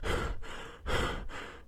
low_energy_M.ogg